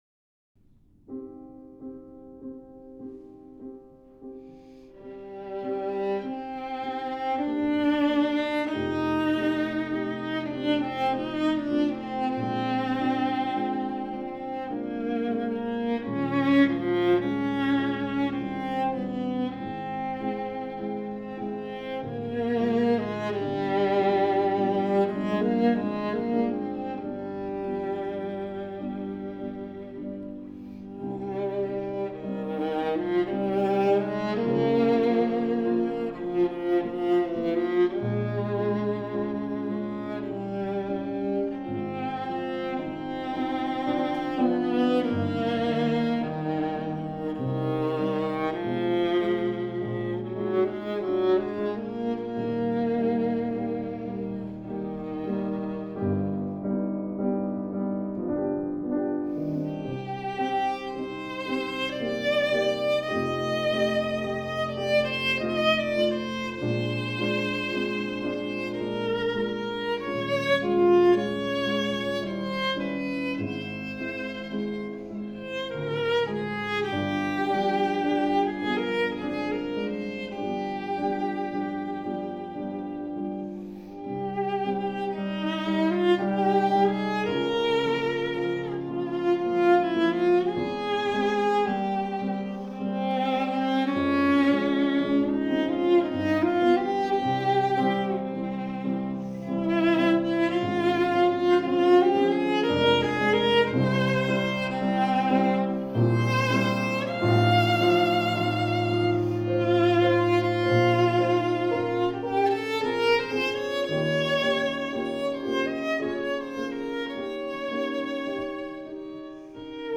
Klangvergleich
Viola A:
(Klavier)